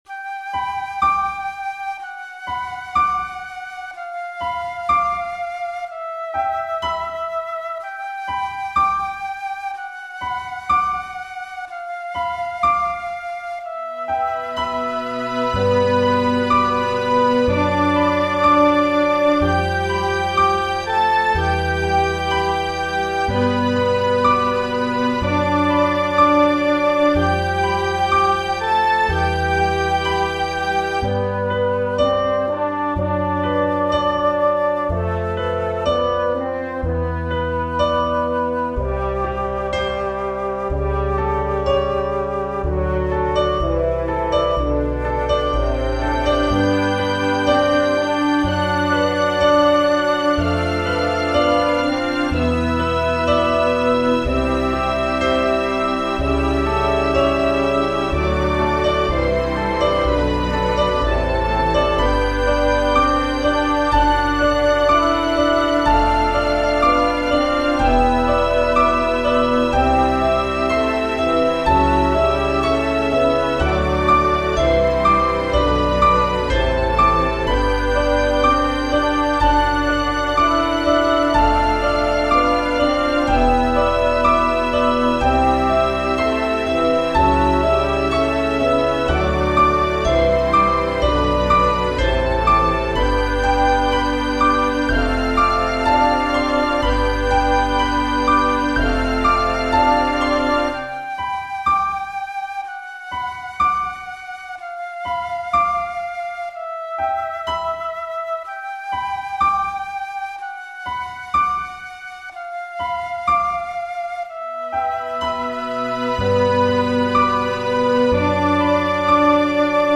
オーケストラ風にするために、色々と参考にしました。